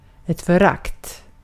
Ääntäminen
IPA : /kənˈtɛmpt/